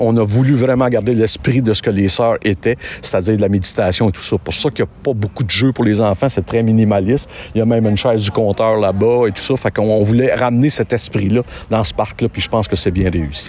Présent lors de la conférence de presse, le conseiller, Denis Jutras, a relaté l’esprit que